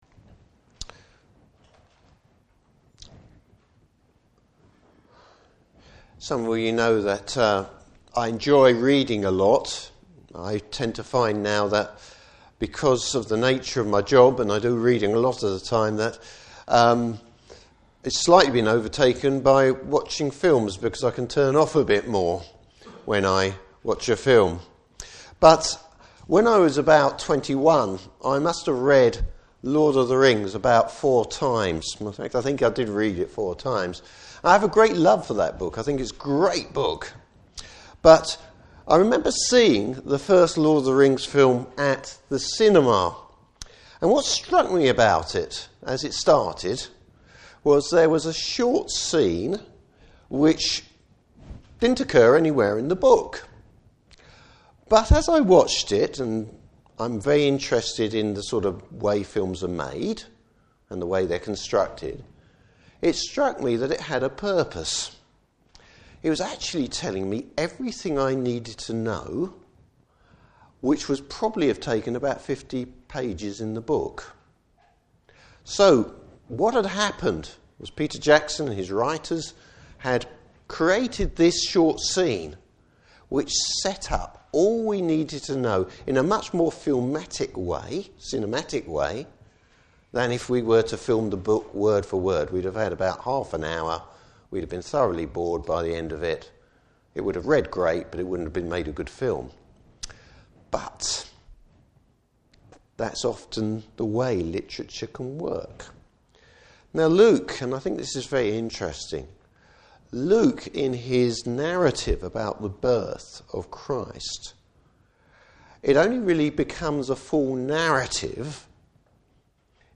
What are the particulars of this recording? Service Type: Morning Service Bible Text: Luke 2:1-7.